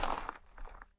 step.wav